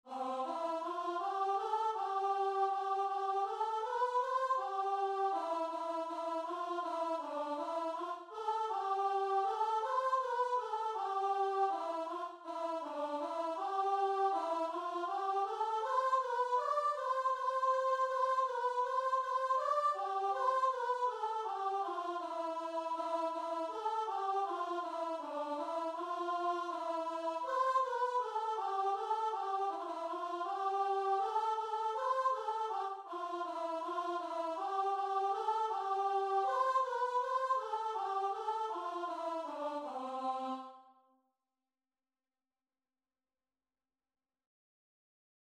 Classical Trad. Alma Redemptoris Mater Choir version
Traditional Music of unknown author.
C major (Sounding Pitch) (View more C major Music for Choir )
Christian (View more Christian Choir Music)